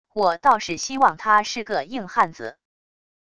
我倒是希望他是个硬汉子wav音频生成系统WAV Audio Player